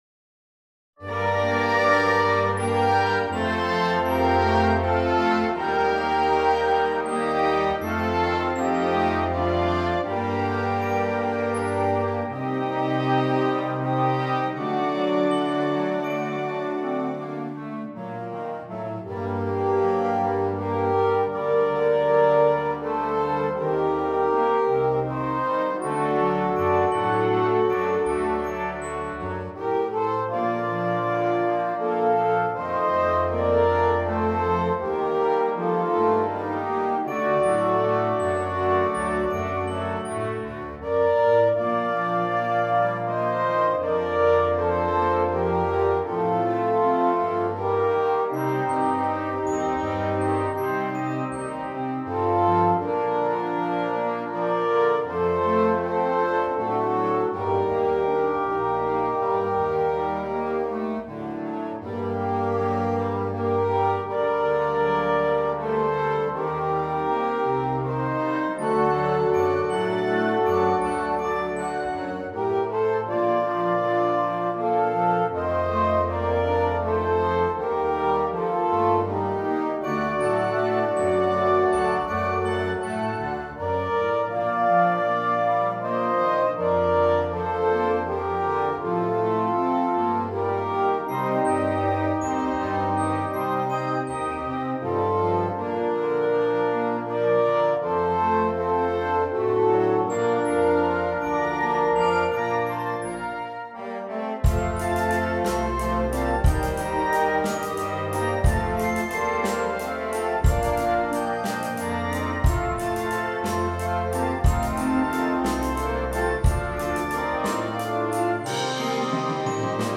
Weihnachtliches Lied für großes Blasorchester
Blasmusik